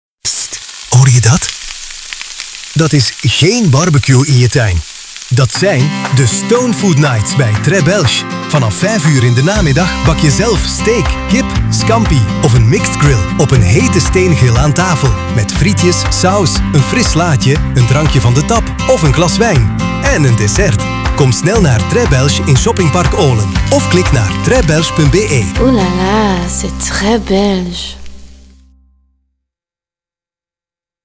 Radio Commercial - Très Belge | AINGELS
Radiospot voor een restaurant